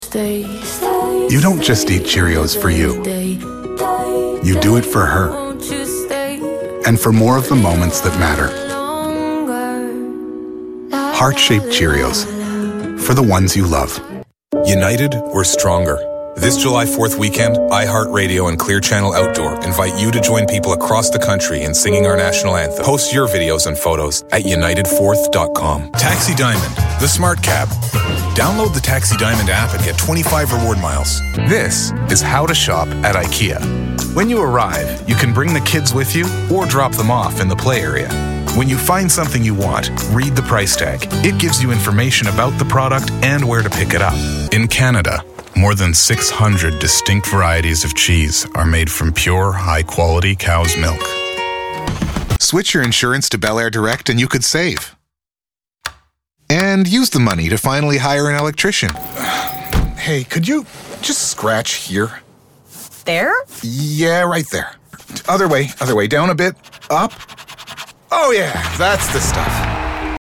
English (Canadian)
Trustworthy
Authoritative
Conversational